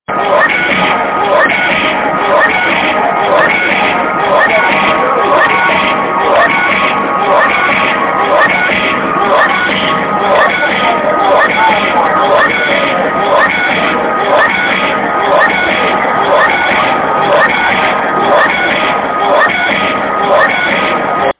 arcade arcade-cabinet effect game malfunction repetition retro-game sfx sound effect free sound royalty free Gaming